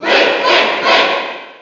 File:Link Cheer NTSC SSB4.ogg
Link_Cheer_NTSC_SSB4.ogg